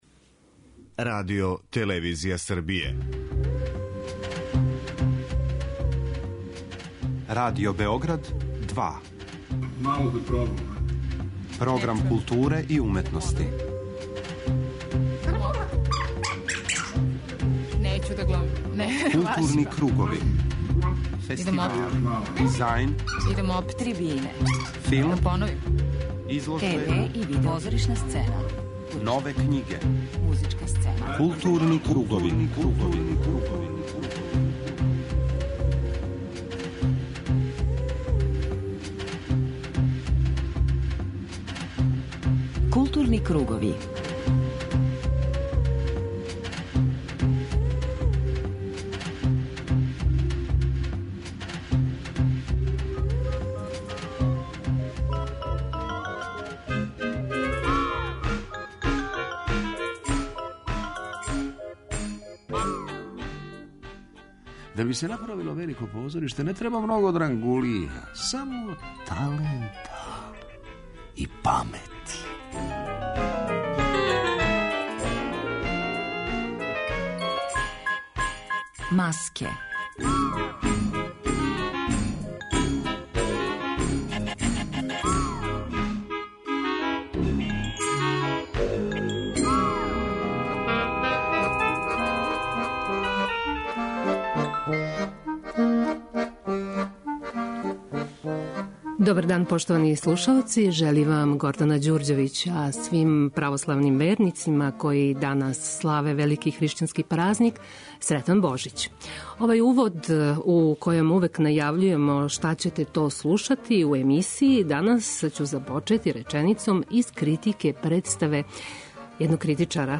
На представе, премијере, и остале занимљивости у протеклој години, осврнућемо се у данашњим Маскама у разговору са гошћама - новинаркама које су пратиле позоришна дешавања.